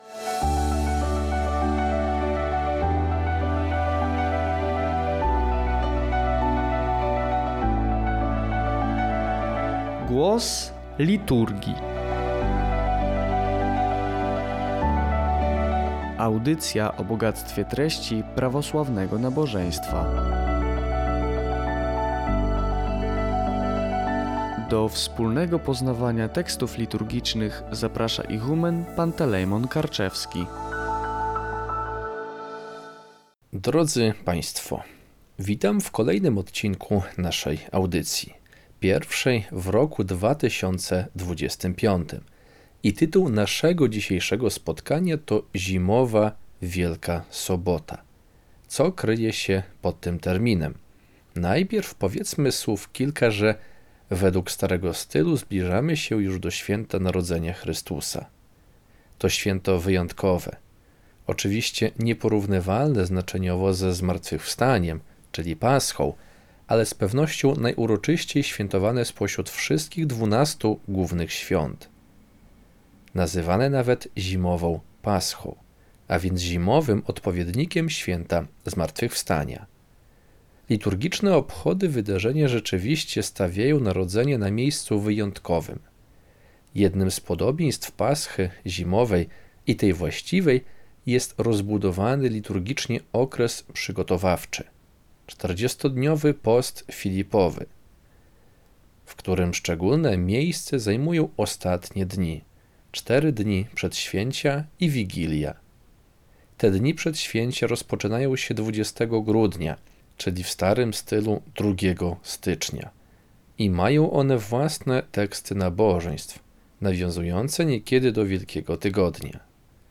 Audycja „Głos Liturgii” co dwa tygodnie.